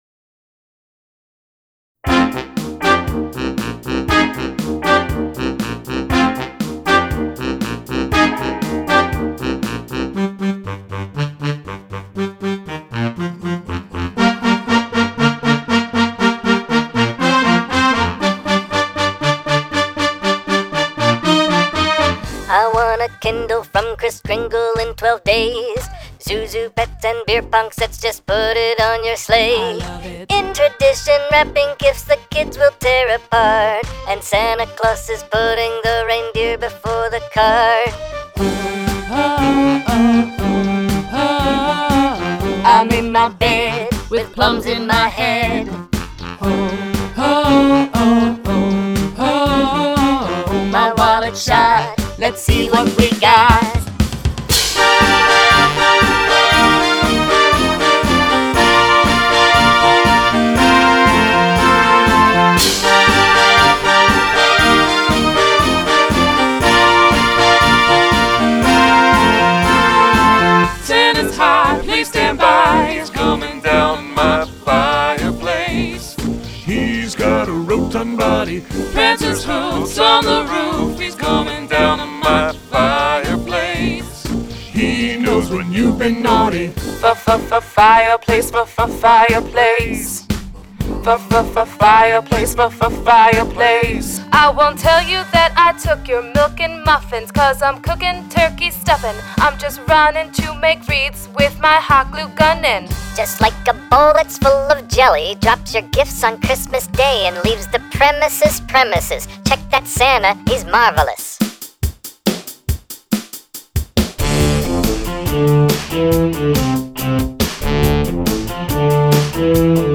2009 Radio Parody